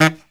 HIHITSAX06-L.wav